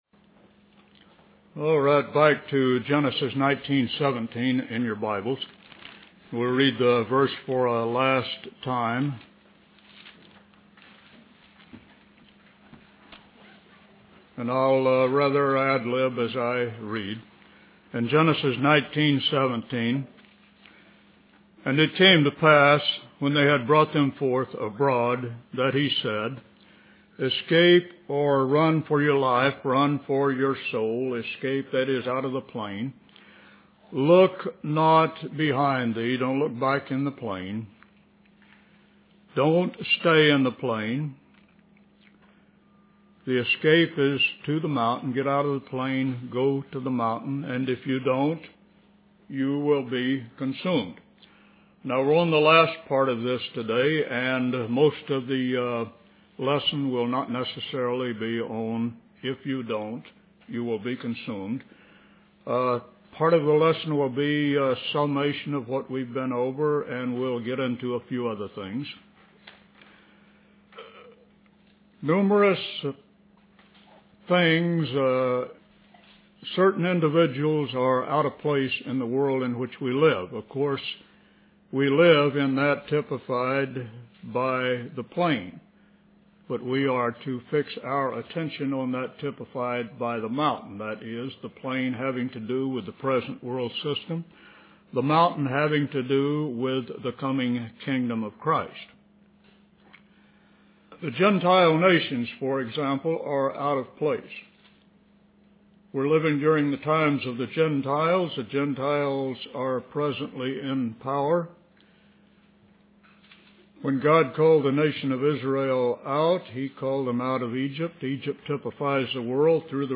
In this sermon, the speaker focuses on the book of Genesis, specifically chapters 1 through 4.